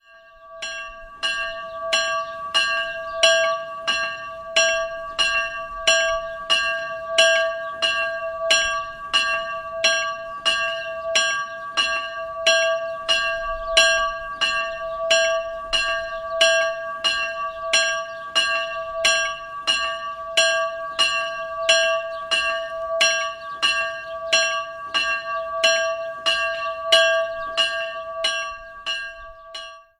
Die Glocke der Kapelle Mariä Unbefleckte Empfängnis in Stetterhof
Klicken Sie hier, um das Geläut anzuhören: